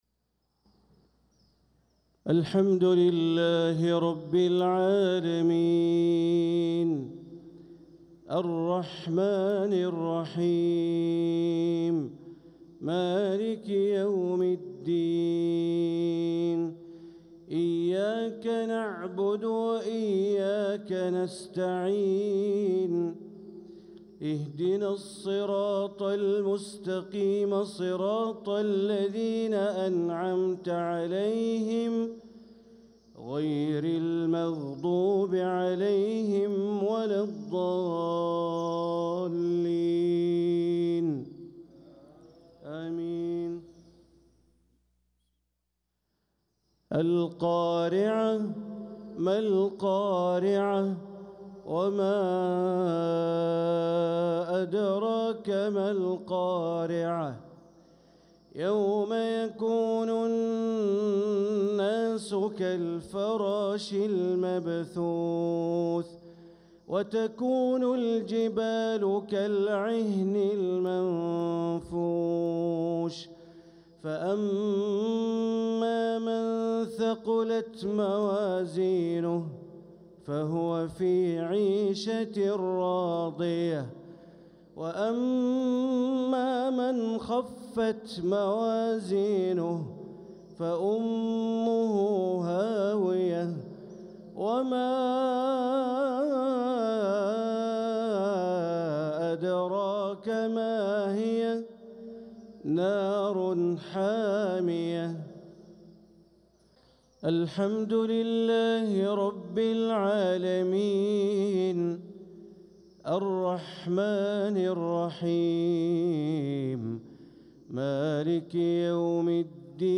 صلاة المغرب للقارئ بندر بليلة 27 ربيع الأول 1446 هـ
تِلَاوَات الْحَرَمَيْن .